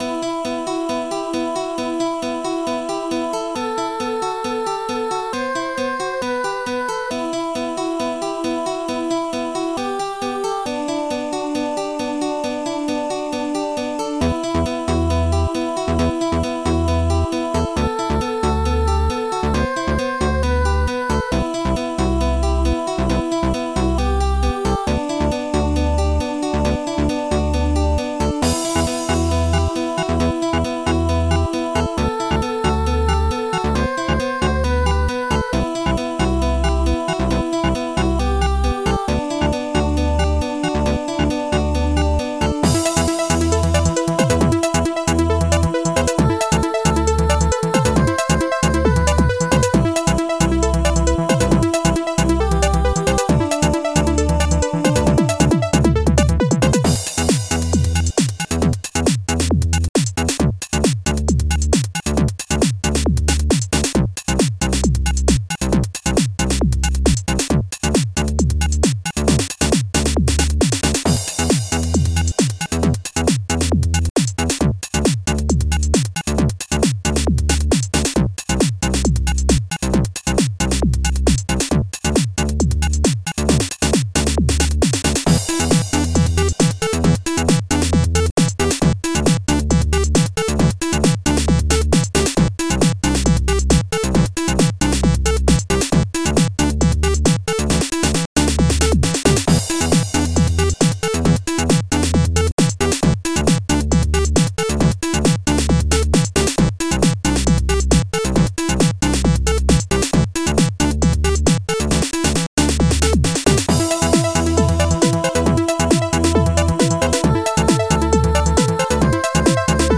Extended Module